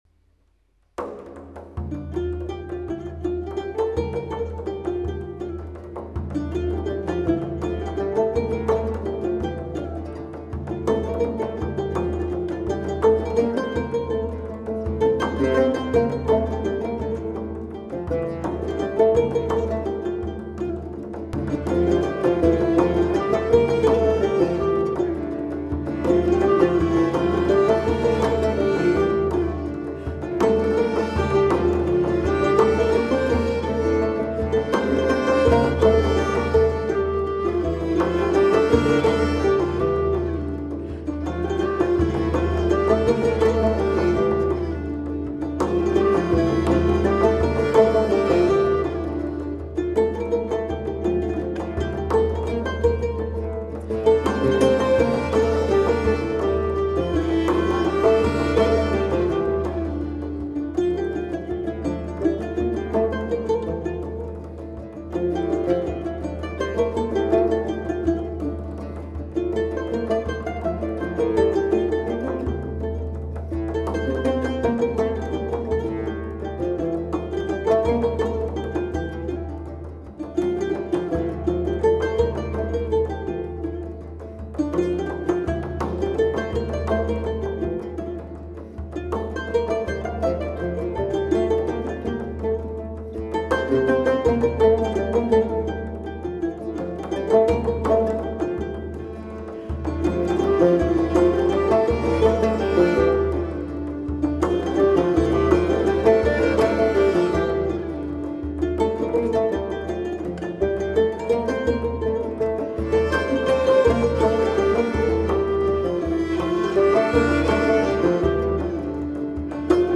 小册子上没有英文，大概是西班牙或是中东的音乐，很好听的古乐，特别是其中的鼓，让人想起西藏高原的藏鼓！